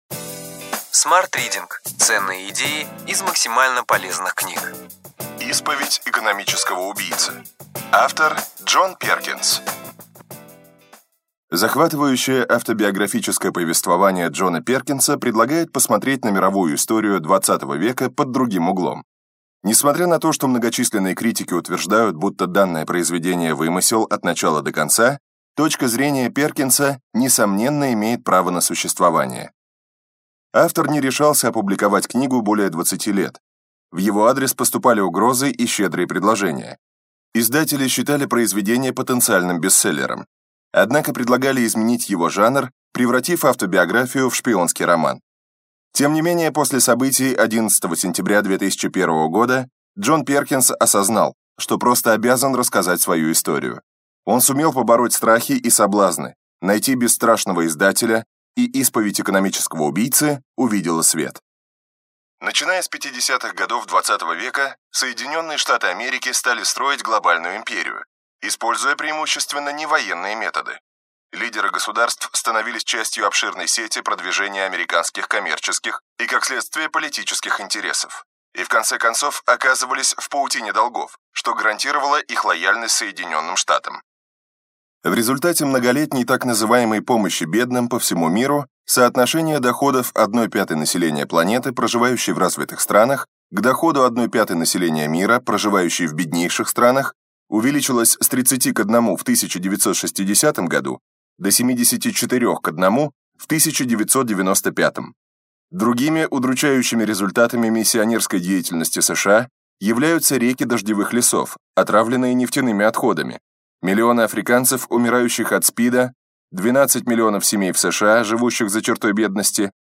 Аудиокнига Ключевые идеи книги: Исповедь экономического убийцы.